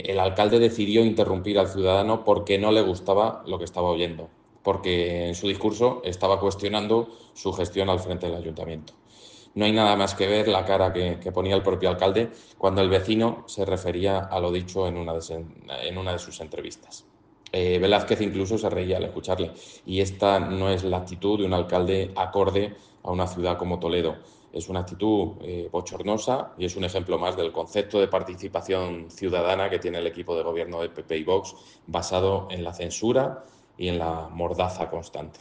El Grupo Municipal Socialista no quiere dejar pasar por alto la censura que impuso el alcalde Carlos Velázquez en el pasado pleno del Ayuntamiento de Toledo impidiendo hablar a un ciudadano que solicitó tomar la palabra de forma reglamentaria en la cámara municipal.
El alcalde decidió interrumpir al ciudadano porque no le gustaba lo que estaba oyendo, porque en su discurso estaba cuestionando su gestión al frente del Consistorio toledano en relación al tema sobre el que había solicitado intervenir.